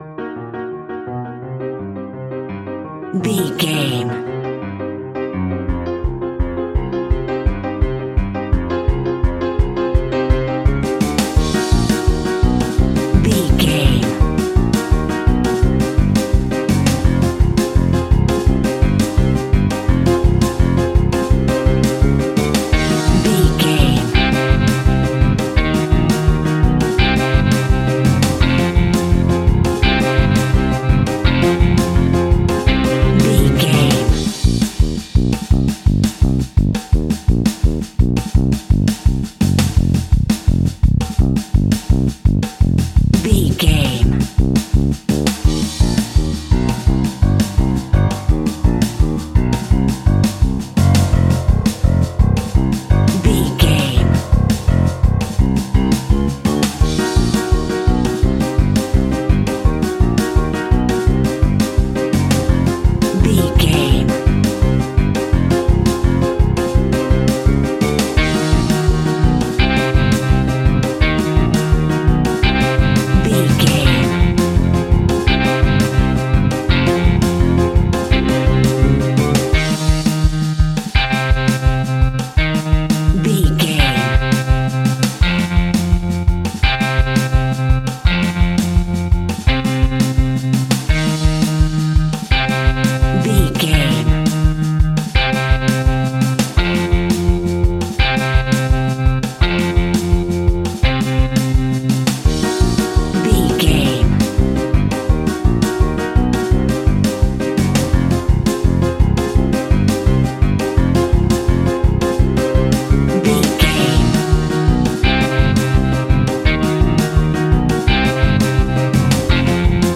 Atonal
ominous
haunting
eerie
instrumentals
horror music
Horror Pads
horror piano
Horror Synths